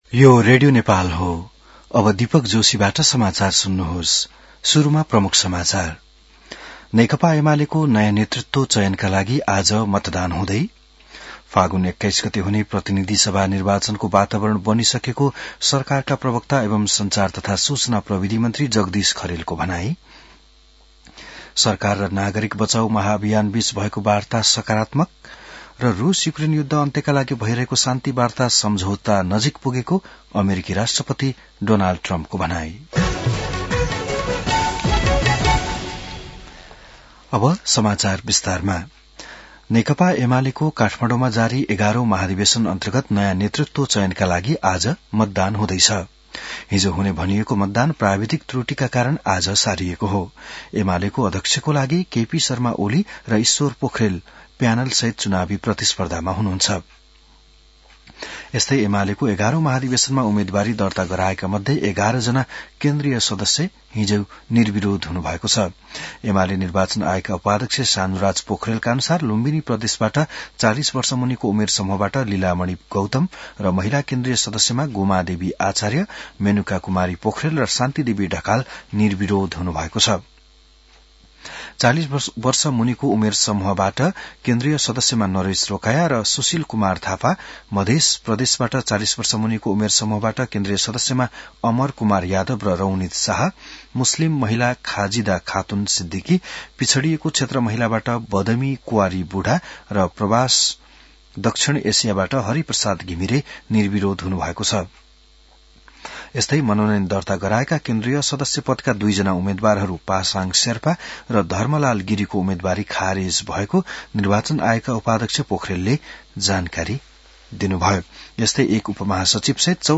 बिहान ९ बजेको नेपाली समाचार : २ पुष , २०८२